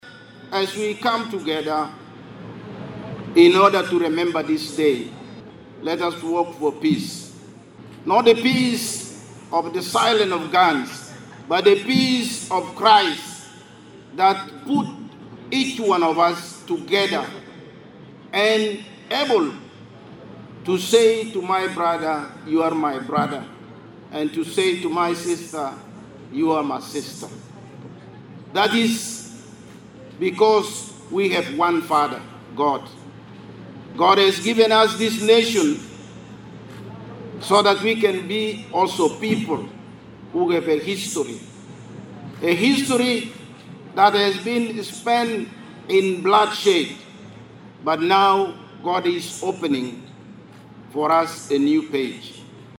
Hundreds of Christians gathered at Juba International Airport to welcome the first-ever Cardinal in South Sudan’s history of the Catholic Church.
Cardinal-Ameyu-Arrival-CLIP2.mp3